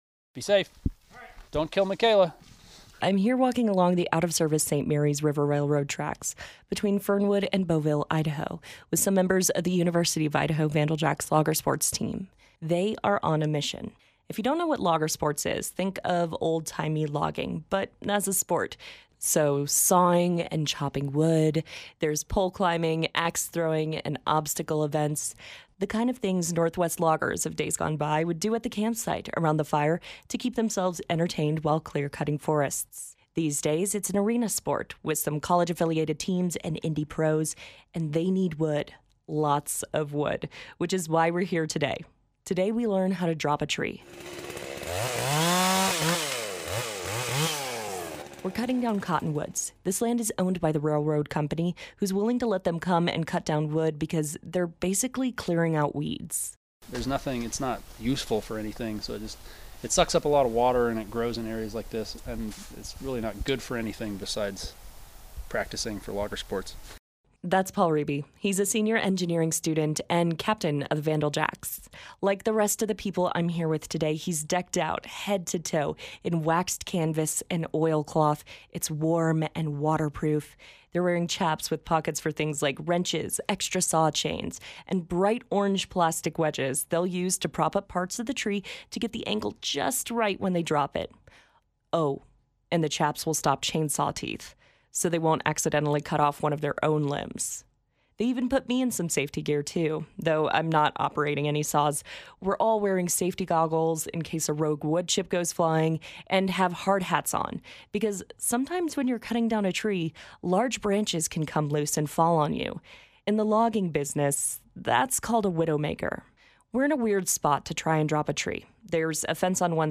There is a loud crack and the tree starts to fall.